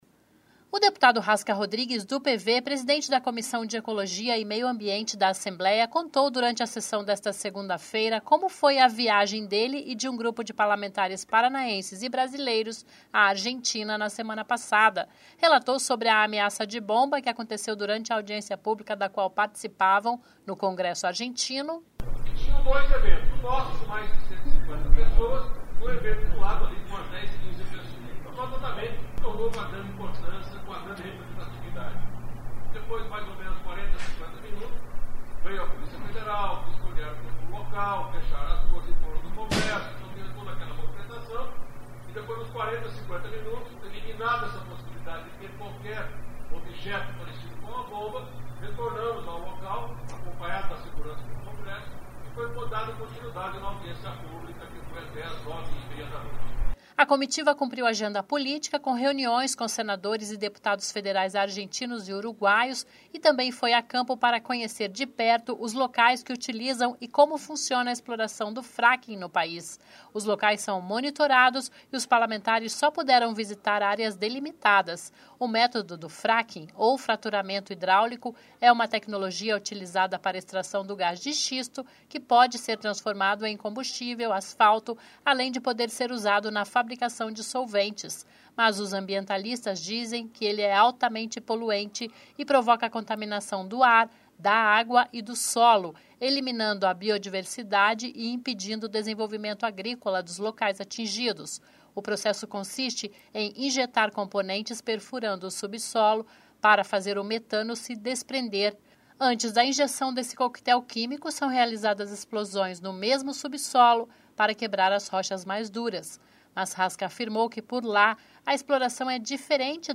O deputado Rasca Rodrigues (PV) presidente da Comissão de Ecologia e Meio Ambiente da Assembleia, contou, durante a sessão desta segunda (9), como foi a viagem dele e de  um grupo de parlamentares paranaenses e brasileiros à Argentina, na semana passada.
O deputado Márcio Nunes (PSC) que também foi à Argentina, demonstrou preocupação com uma possível exploração do xisto que pode ser feita na fronteira do Brasil com a Argentina.